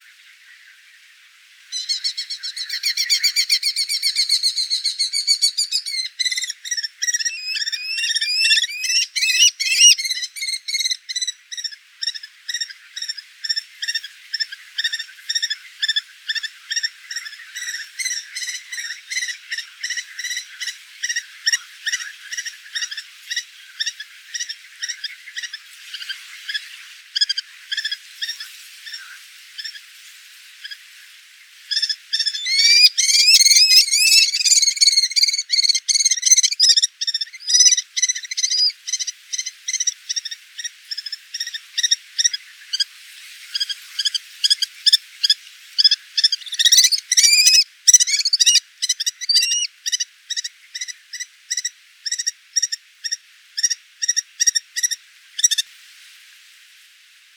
Вы услышите разнообразные голосовые вариации: от характерных резких криков до более мягких перекличек. Записи сделаны в дикой природе разных регионов, что позволяет оценить особенности вокализации птицы.
Птица в естественной среде обитания